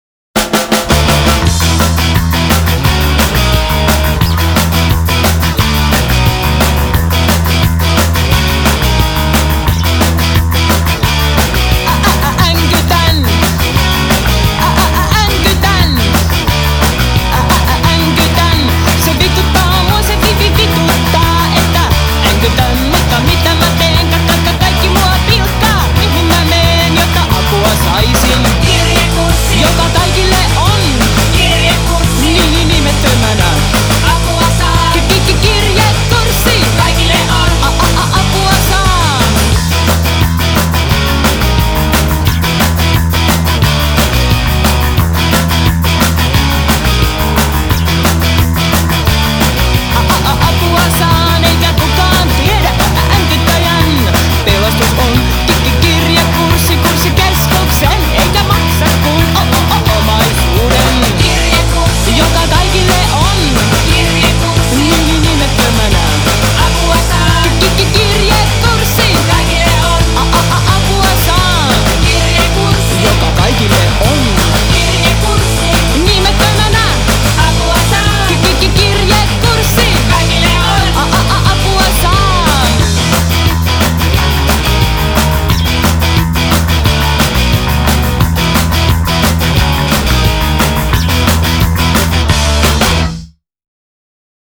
kitara, taustalaulut
basso, taustalaulut
rummut, taustalaulut
treenikämpällä ja Riku-studiossa